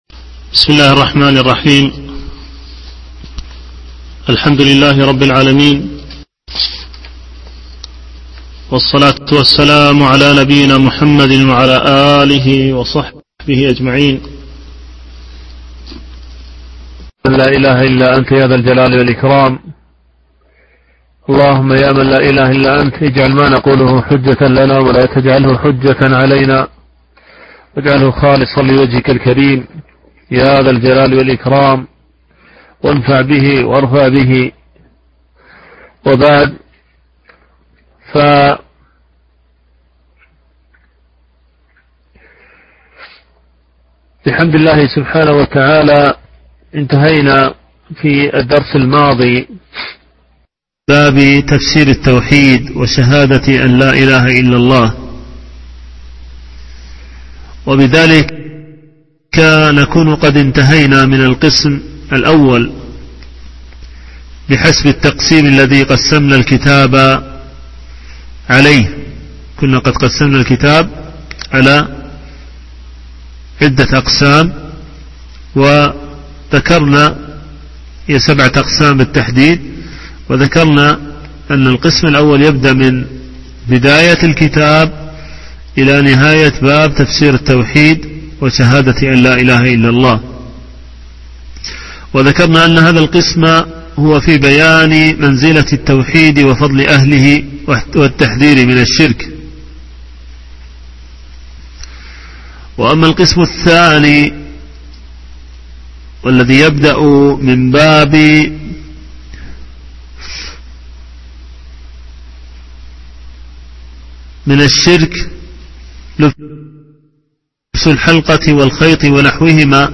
الدرس 8